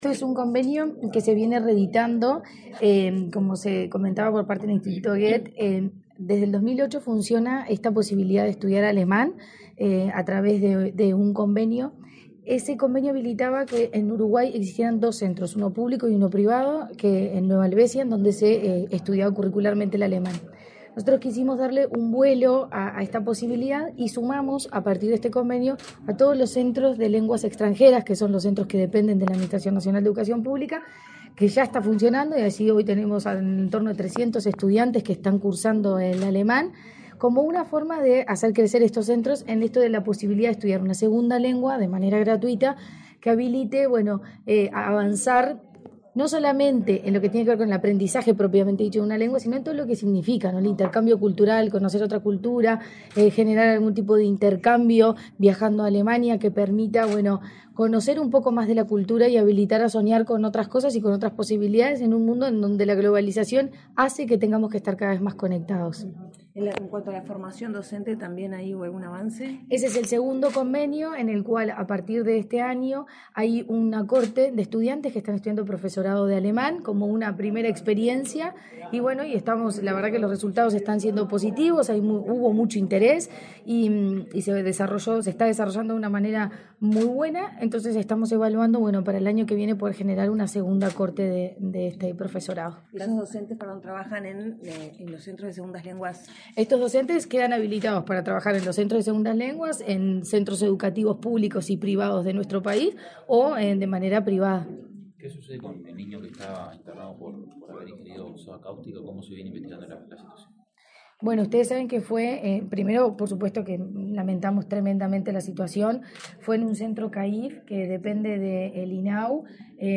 Declaraciones de la presidenta de la ANEP, Virginia Cáceres
Declaraciones de la presidenta de la ANEP, Virginia Cáceres 18/09/2024 Compartir Facebook X Copiar enlace WhatsApp LinkedIn La Administración Nacional de Educación Pública (ANEP) suscribió un nuevo convenio con el Goethe Institut Uruguay, con el objetivo de promover la enseñanza y el aprendizaje de la lengua alemana en distintos centros educativos públicos. La presidenta de la ANEP, Virginia Cáceres, explicó a la prensa, el alcance del acuerdo.